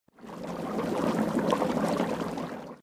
boiling_water.ogg